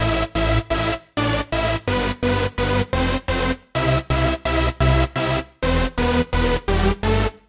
标签： 128 bpm House Loops Synth Loops 1.26 MB wav Key : Unknown
声道立体声